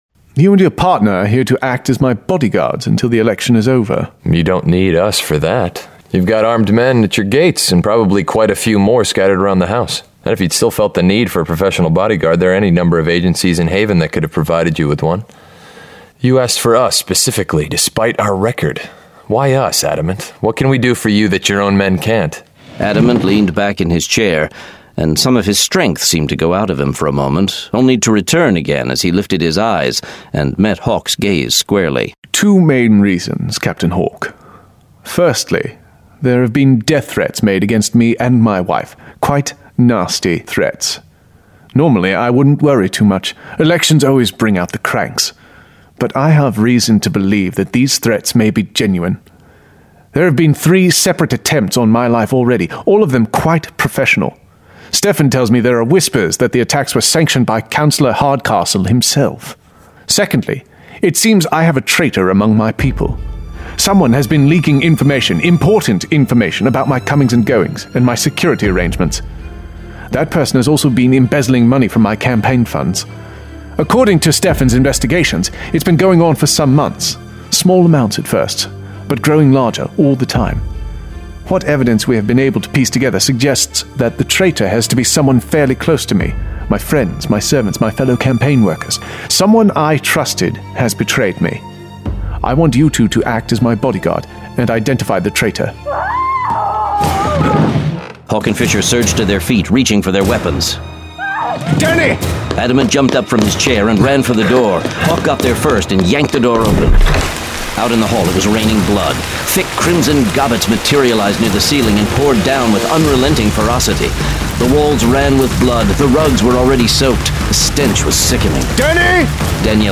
Full Cast. Cinematic Music. Sound Effects.
[Dramatized Adaptation]